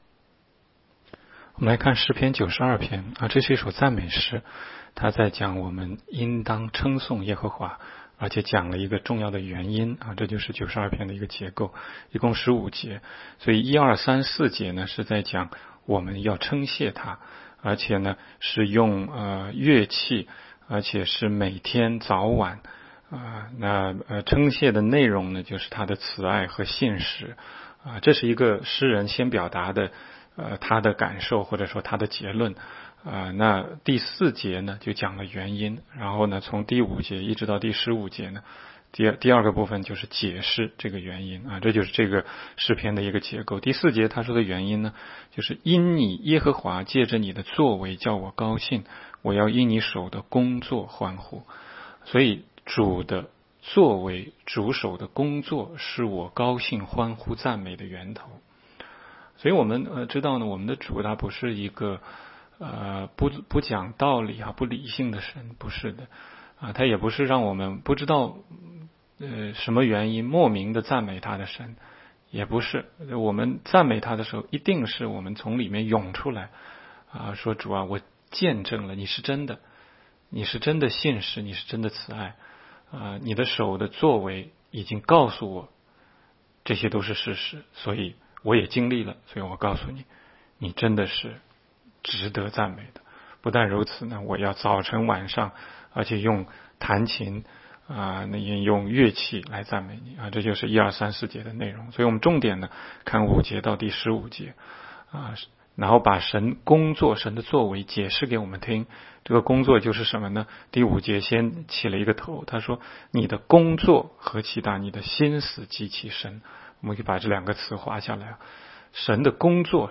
16街讲道录音 - 每日读经-《诗篇》92章